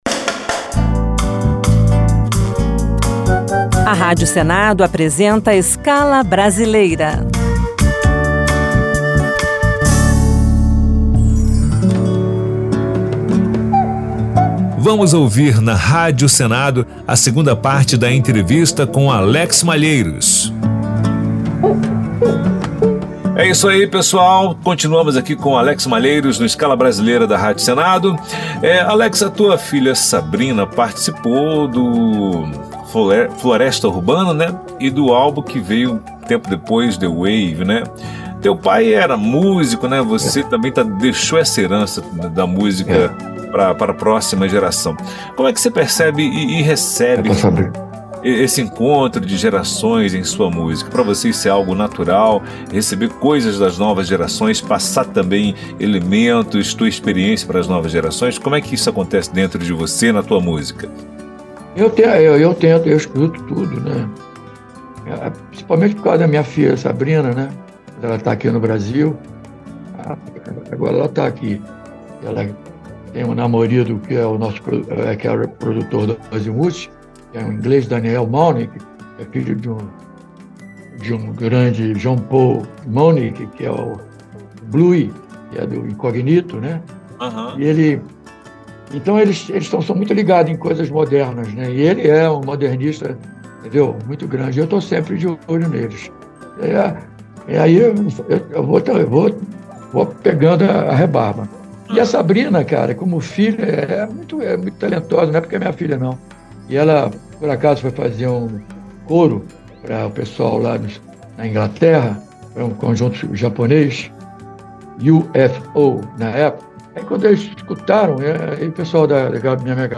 Nesta segunda parte da entrevista com Alex Malheiros, ele fala principalmente sobre seus álbuns. O músico, integrante original do Azymuth e um dos nomes mais requisitados para gravações em estúdio na história da música brasileira, detalha sobre a concepção e o processo de produção de seus projetos solo.